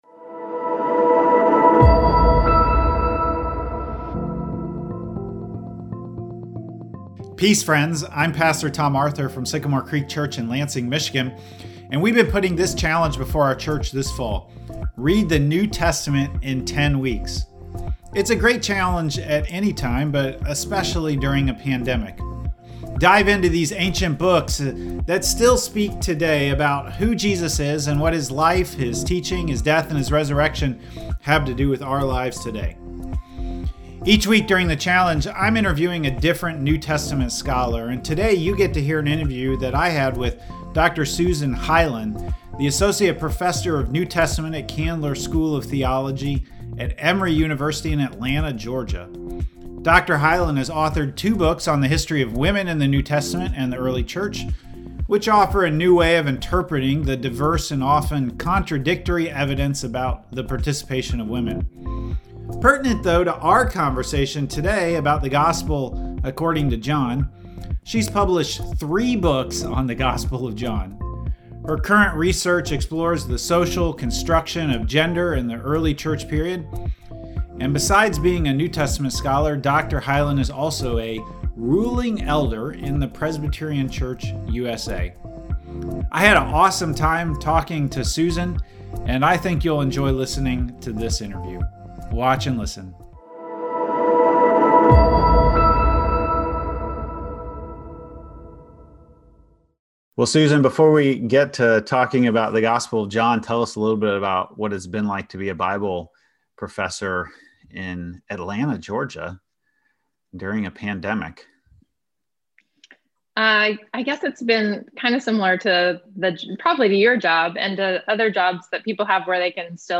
Scholar Interview